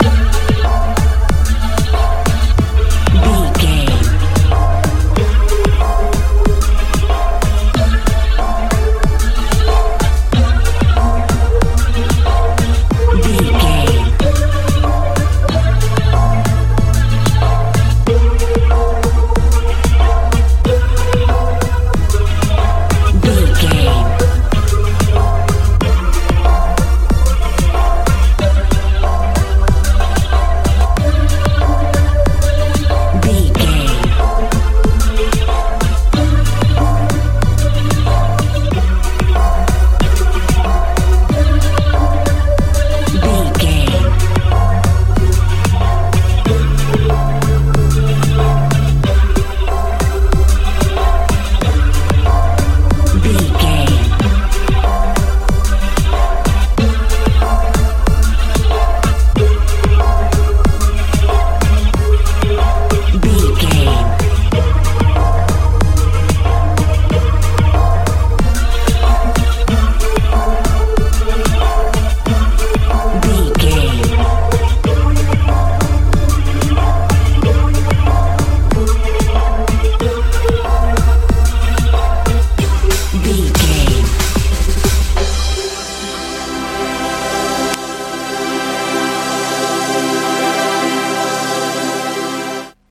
house feel
Ionian/Major
D
disturbing
strange
synthesiser
bass guitar
drums
80s
90s